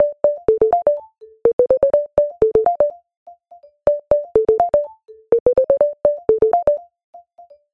最低限度的Perc Melo
描述：最小/技术
Tag: 124 bpm Electronic Loops Percussion Loops 1.96 MB wav Key : Unknown